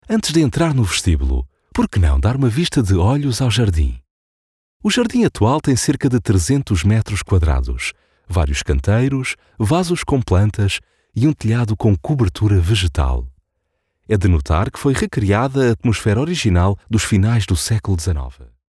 I have a deep low male tone, calm and smooth, usually chosen for corporate, elearning, explainers, narration and commercials.
Tour Guide
Portuguese Audioguide
Words that describe my voice are Portuguese, deep, low tone.